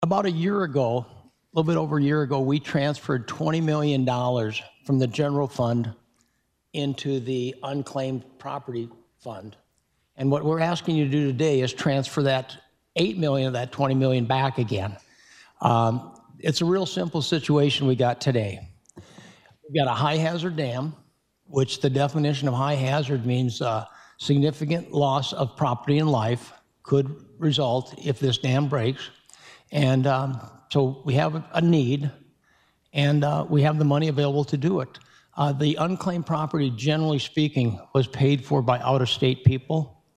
SD House:
Representative Al Novstrup of Aberdeen who was on the committee moved to approved the report.